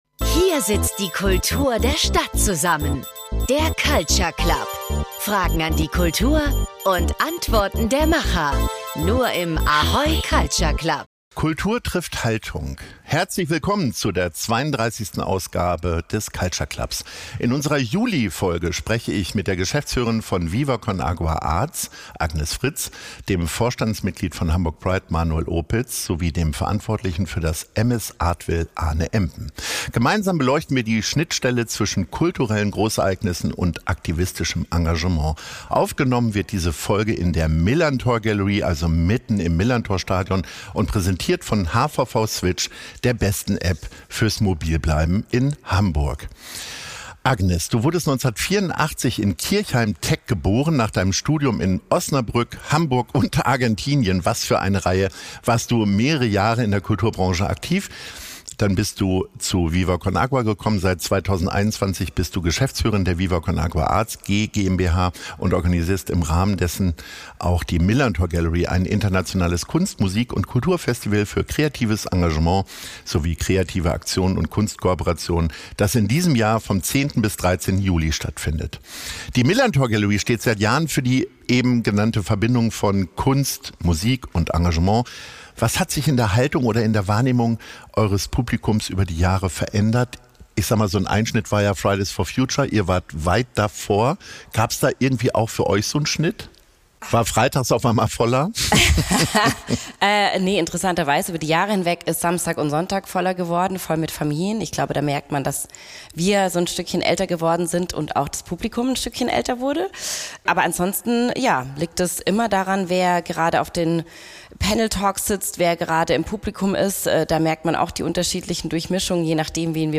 Kultur trifft Haltung ~ Culture Club - Der Kulturtalk bei ahoy, präsentiert von hvv switch Podcast
Aufgenommen wurde die Folge in der einzigartigen Atmosphäre der Millerntor Gallery direkt im Stadion und präsentiert von HVV Switch, der besten App fürs mobil bleiben in Hamburg.